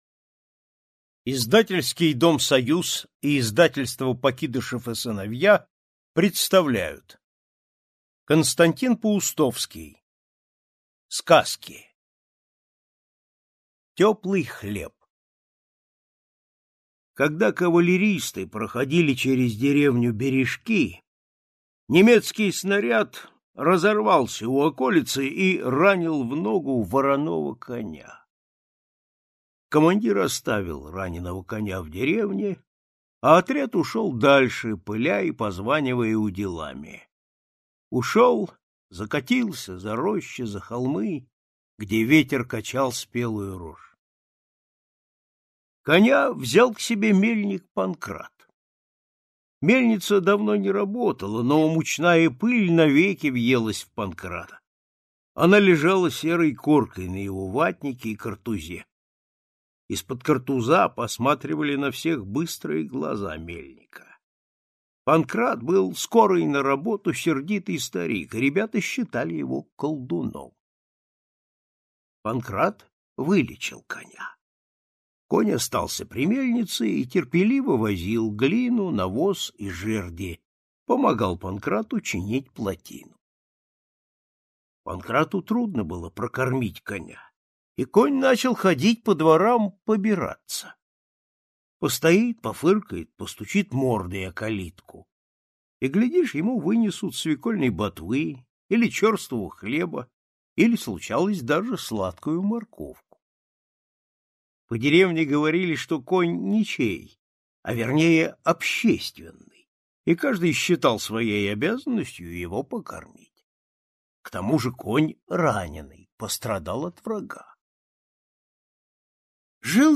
Теплый хлеб - аудио рассказ Паустовского К. Рассказ про мальчика Фильку и раненого коня, которого бойцы оставили в деревне лечиться.